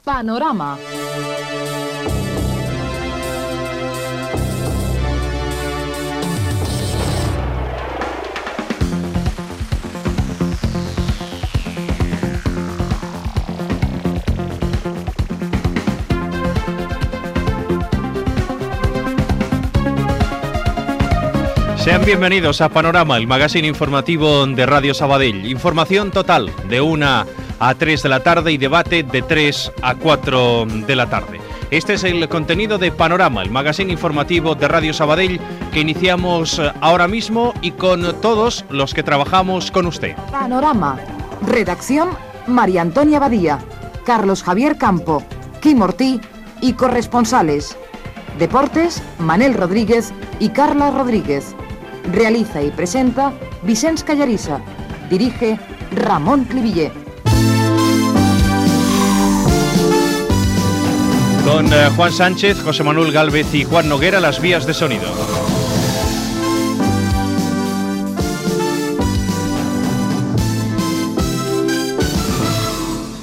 Careta, inici del programa i crèdits
Informatiu